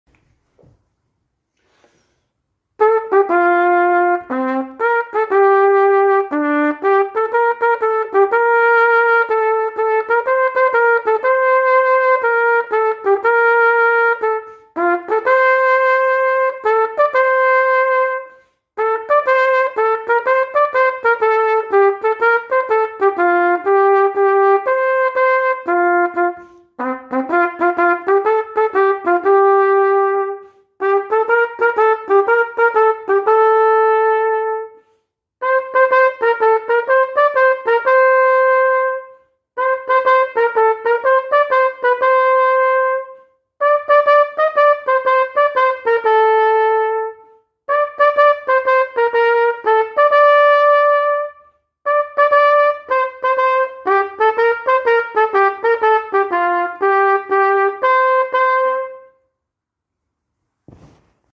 на трубе